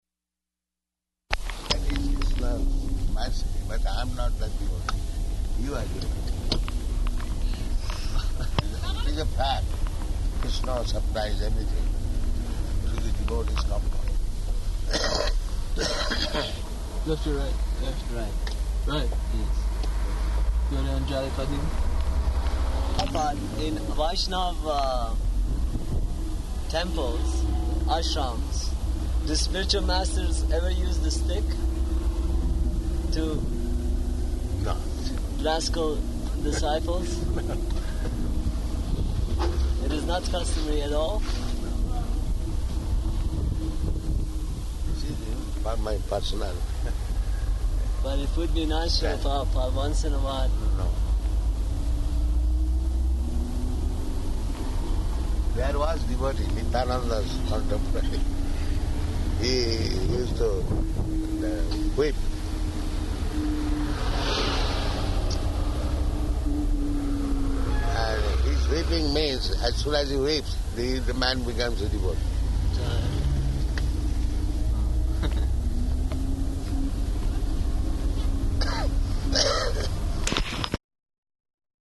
Conversation in Car
Location: Tehran